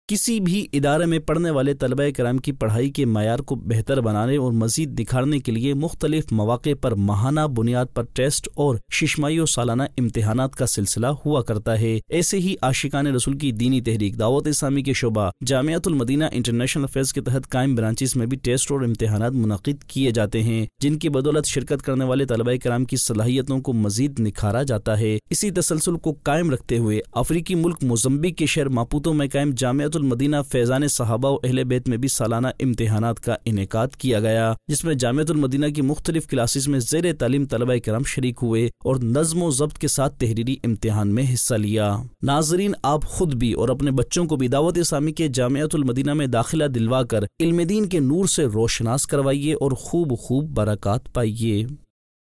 News Clips Urdu - 21 November 2023 -Students Kay Taleemi Mayar Ko Behtar Banane Kay Liye Imtihanaat Ka Aehtamam Kiya Jata Hai Nov 28, 2023 MP3 MP4 MP3 Share نیوز کلپس اردو - 21 نومبر 2023 - اسٹوڈنٹس کے تعلیمی معیار کو بہتر بنانے کے لئے امتحانات کا اہتمام کیا جاتا ہے